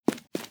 player_sprint.wav